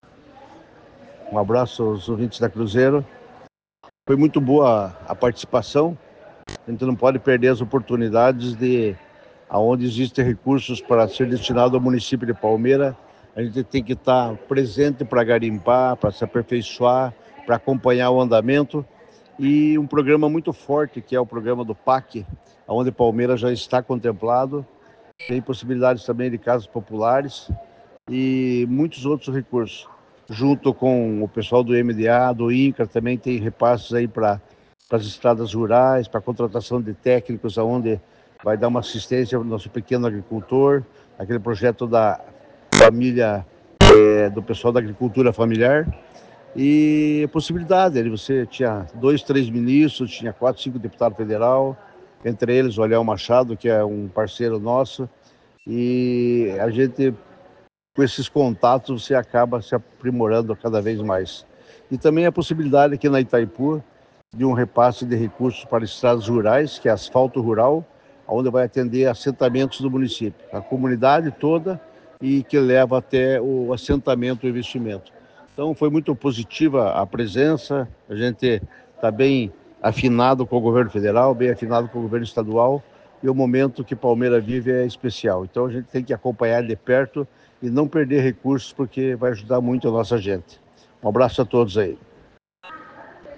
O prefeito Altamir Sanson também conversou com a Rádio Cruzeiro e ressaltou a relevância do encontro. Segundo ele, a Caravana Federativa é uma oportunidade para fortalecer o diálogo com o Governo Federal e buscar novos investimentos para Palmeira.